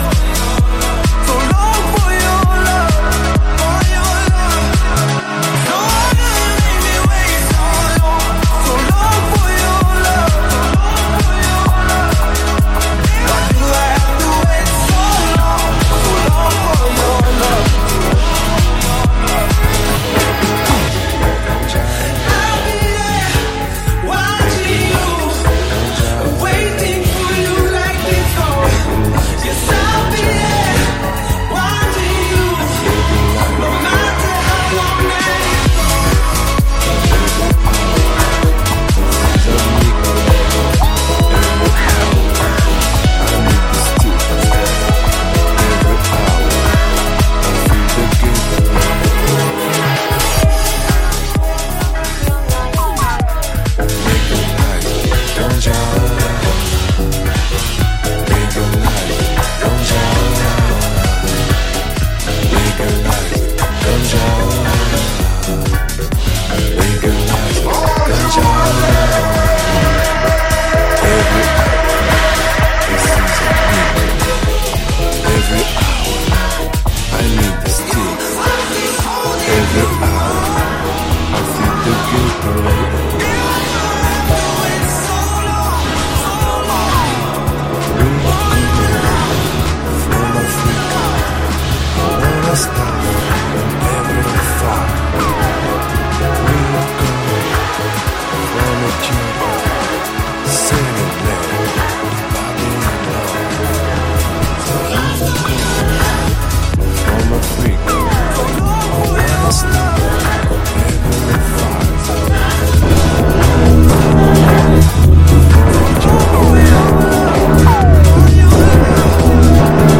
quick little mix
with some samples thrown in